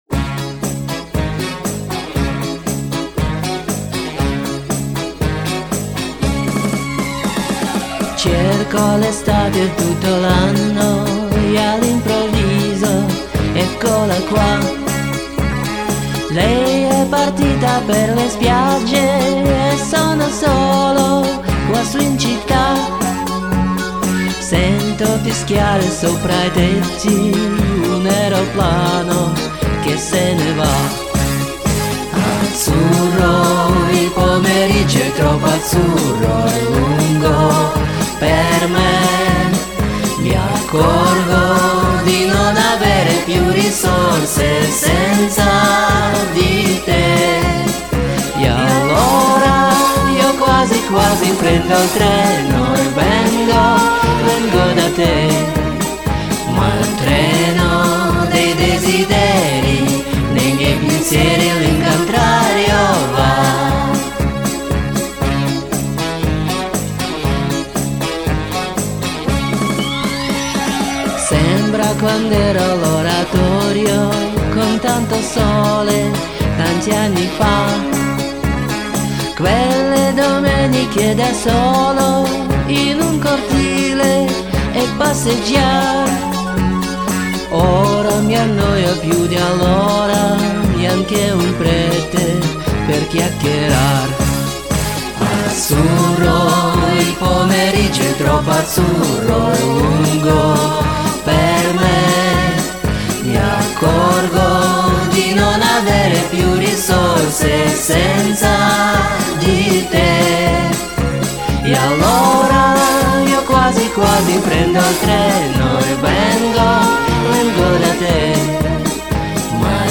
я вместо него и бэков